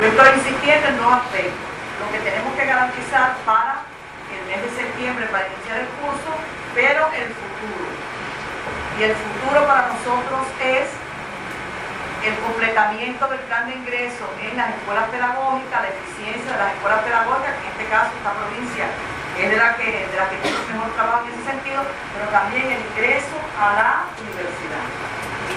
Palabras-de-Ena-Elsa-Velázquez-Cobiella.mp3